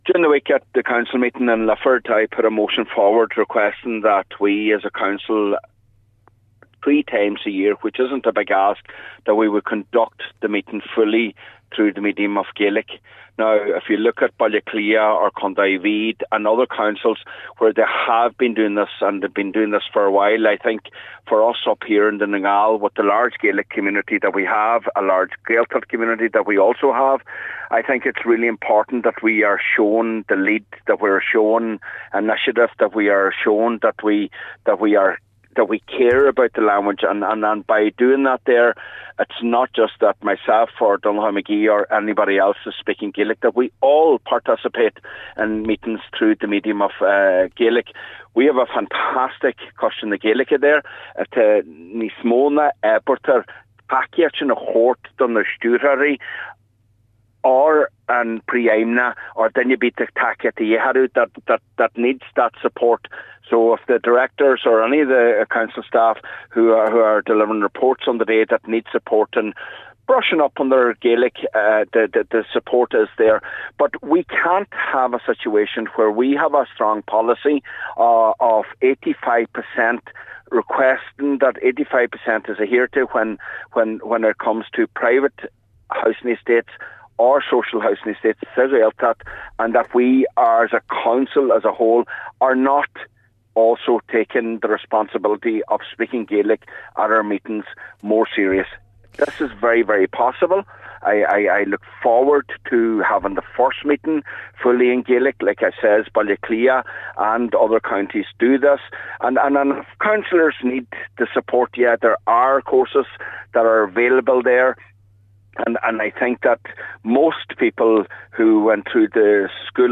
Cllr Mac Giolla Easbuig says there other local authorities doing this without a Gaeltacht, providing it is possible: